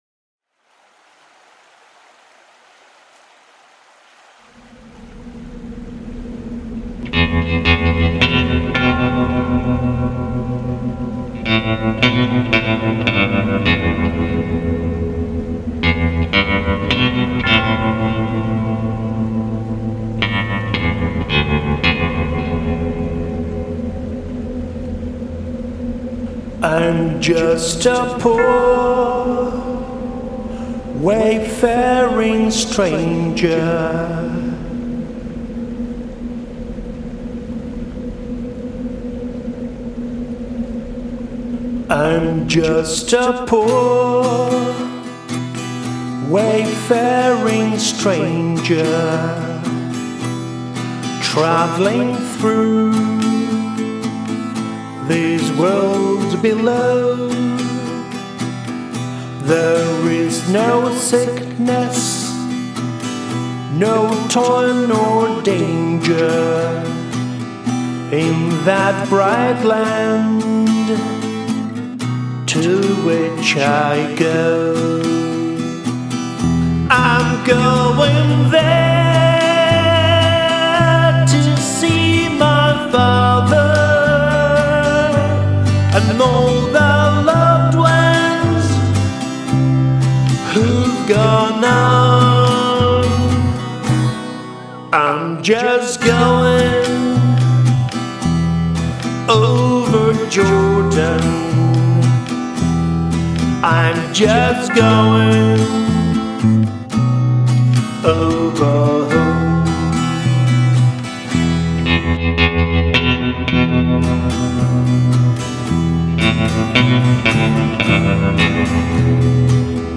Genre: bluegrass, country.